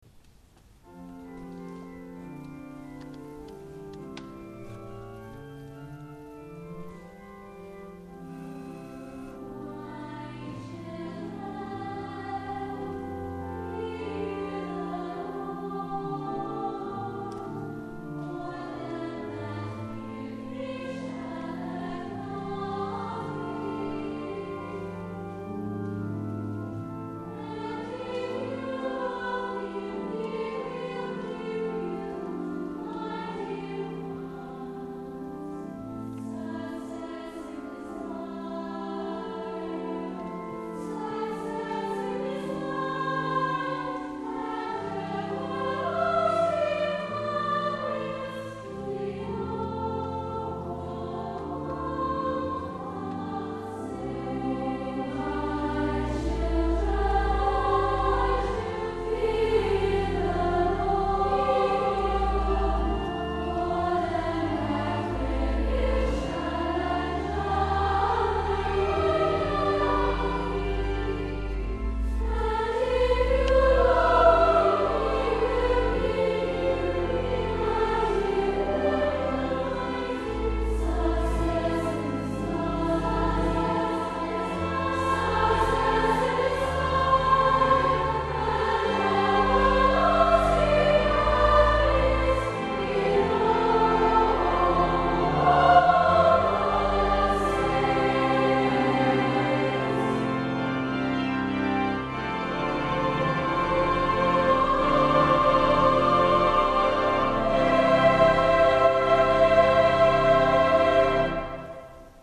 You can hear a setting of these words by Ian Hope as performed on the St Margaret's Schools Choir Tour by clicking